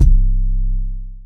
TM88 SouthTM808.wav